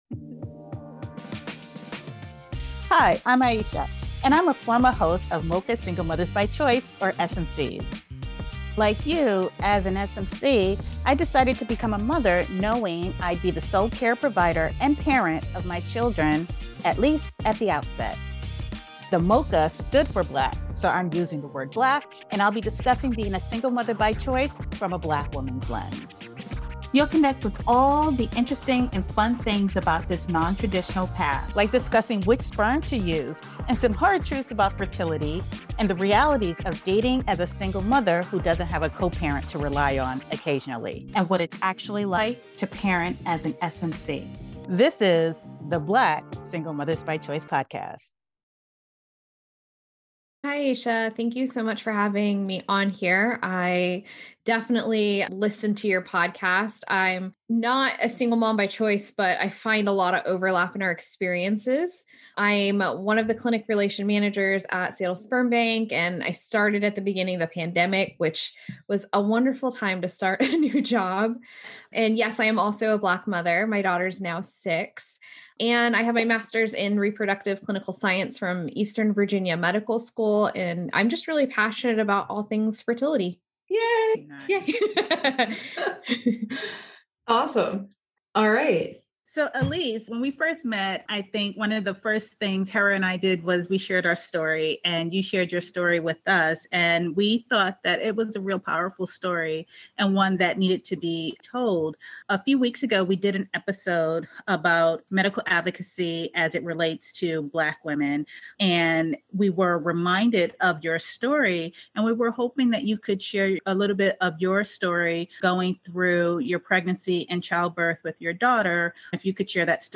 S1E14: A Conversation with Seattle Sperm Bank